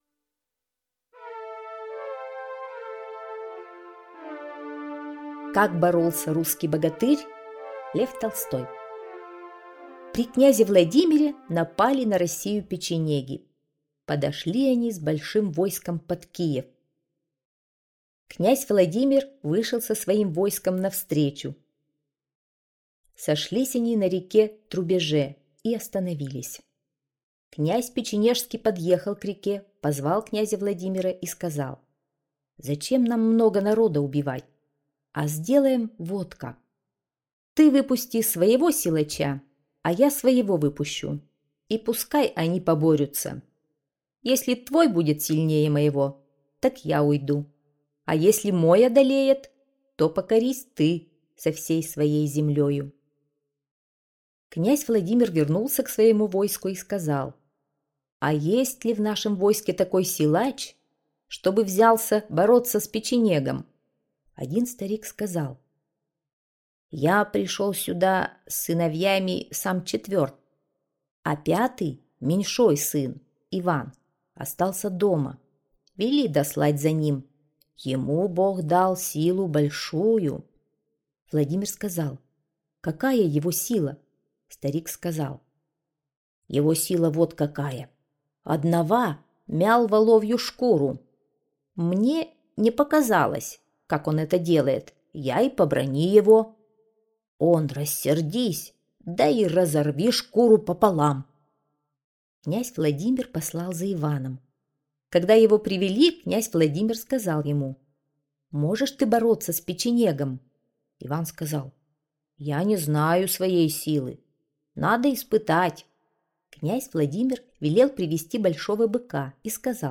Как боролся русский богатырь - аудиосказка Толстого Л.Н. Слушать онлайн сказку Толстого Л.Н. на сайте Мишкины книжки.